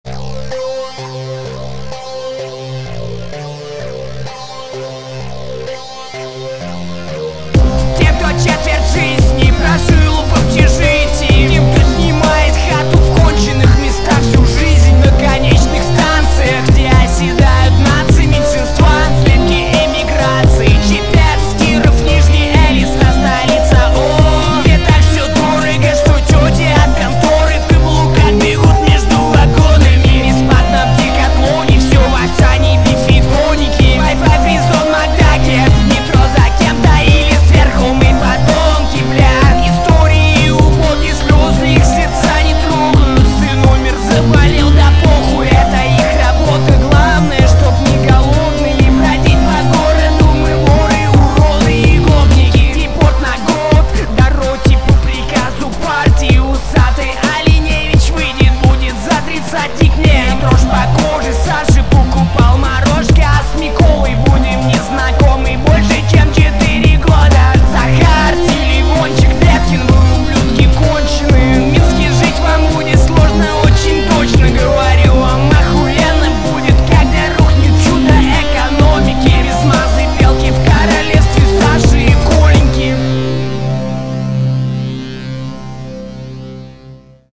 два трека, записанные как всегда в гараже/квартире/подвале/улице, без усилителей, микшеров и прочего ненужного))))))) стафа. только нетбук, наушники с микрофоном, аля контрстрайк ))) (250 руб). прогрмамы — LMMS + Ardour